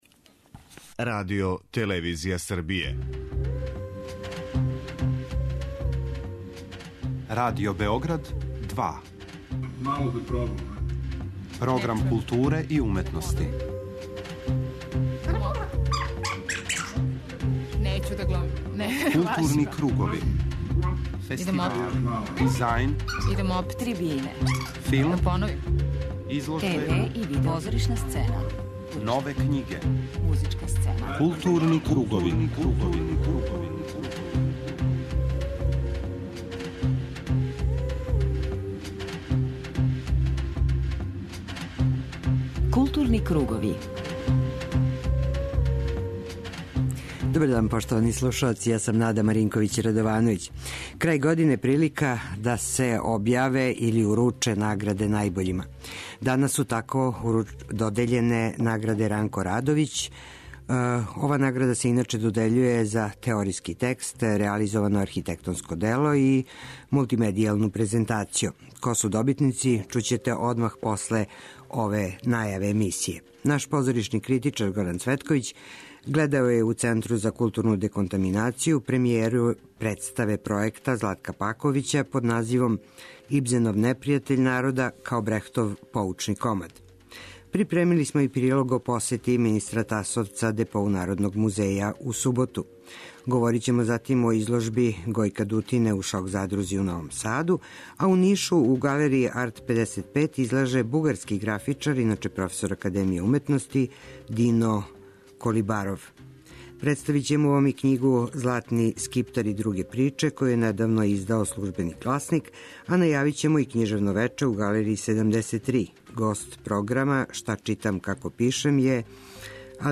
У првом делу данашње емисије говоримо о актуелним културним догађајима, а садржај блока 'Арс сонора' чине прикази четири концерта, одржана у дворани Коларчеве задужбине у Београду.